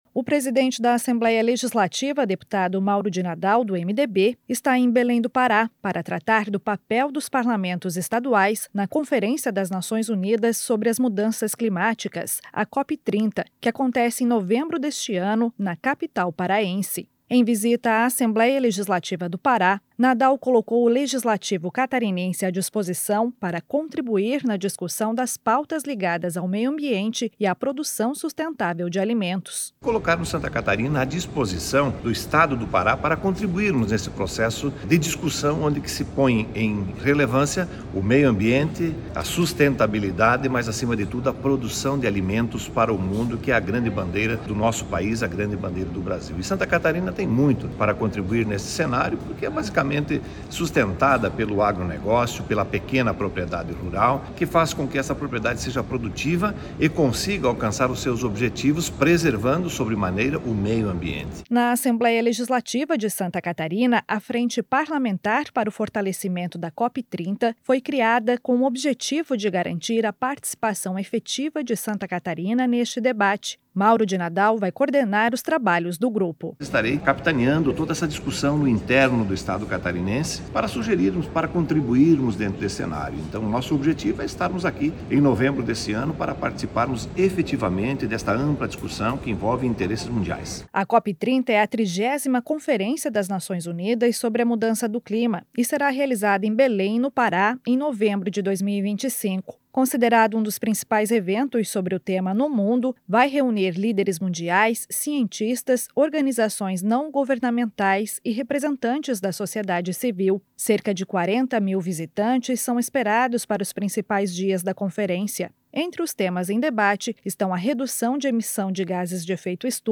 Entrevista com:
- deputado Mauro De Nadal (MDB), presidente da Assembleia Legislativa.